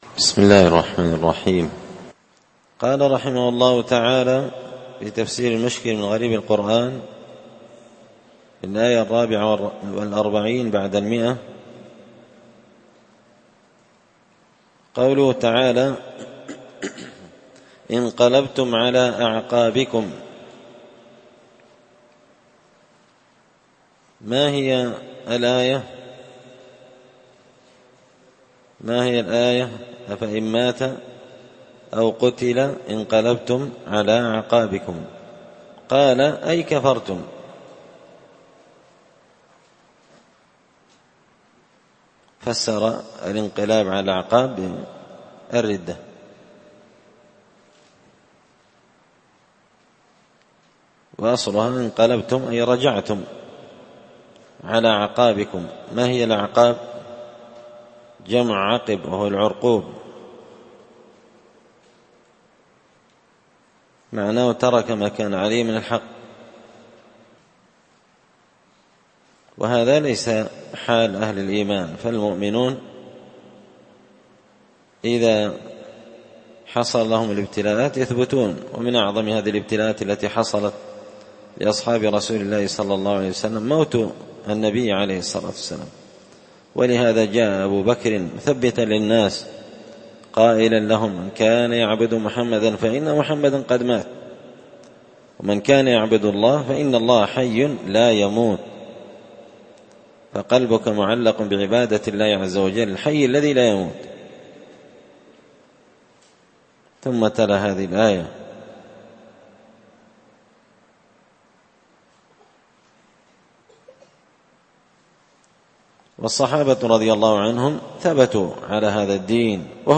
تفسير مشكل غريب القرآن ـ الدرس 73